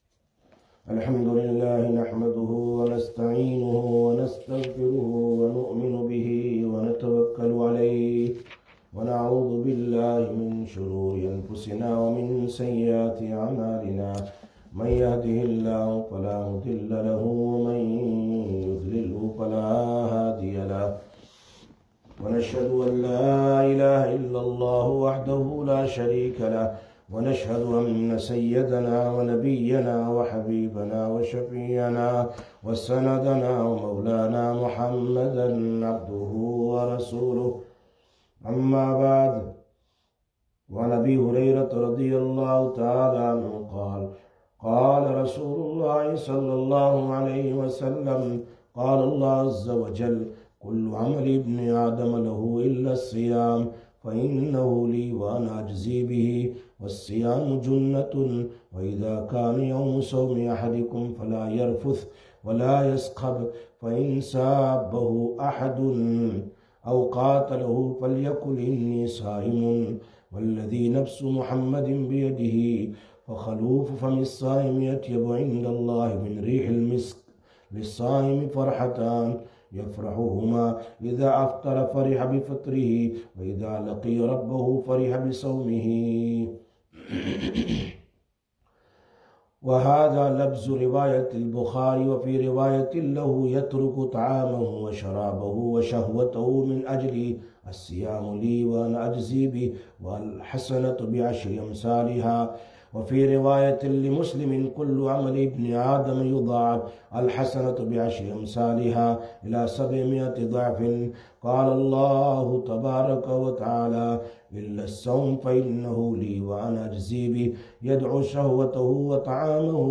14/04/2021 Sisters Bayan, Masjid Quba